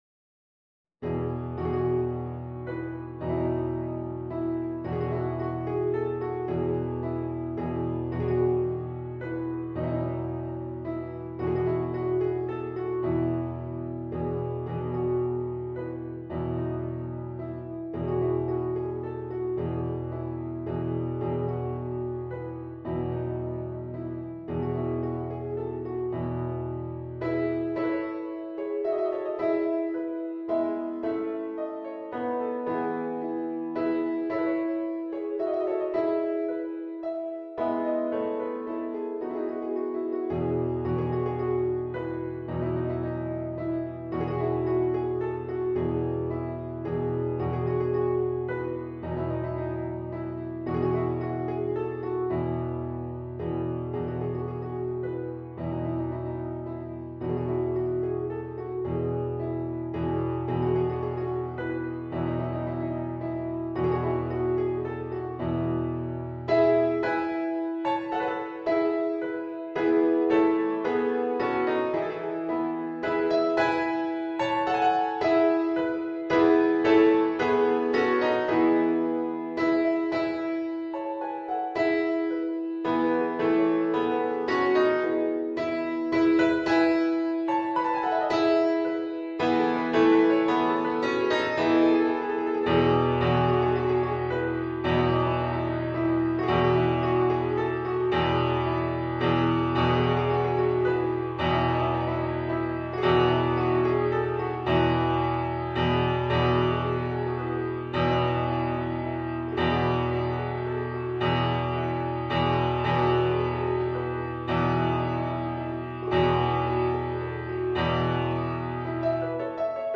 Solo Instrument